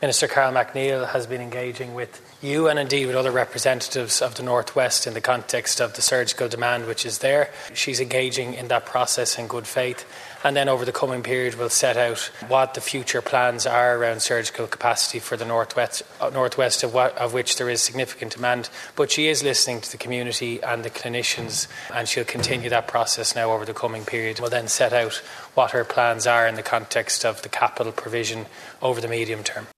Minister Jack Chambers said the health minister continues to listen to everyone……………….